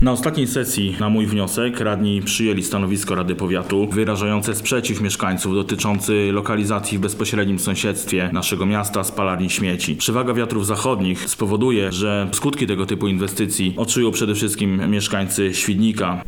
Łukasz Czemerys – mówi Łukasz Czemerys, radny Powiatu Świdnickiego.